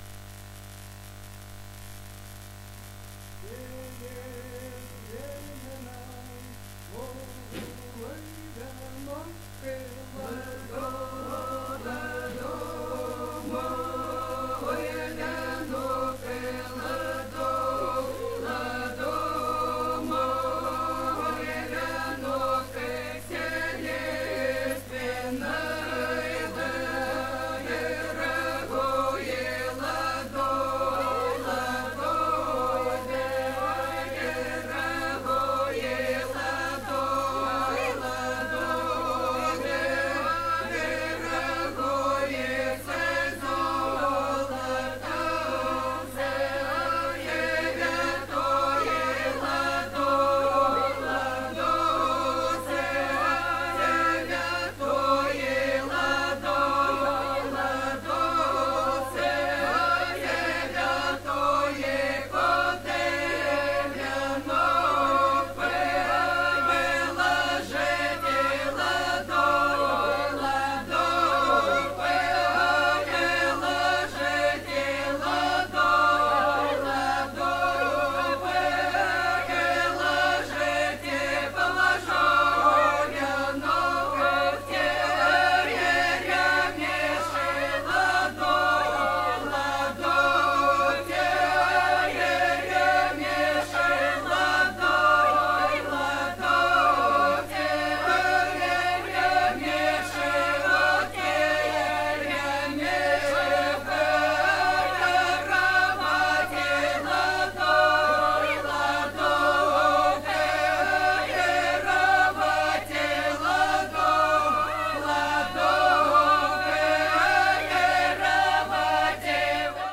※レコードの試聴はノイズが入ります。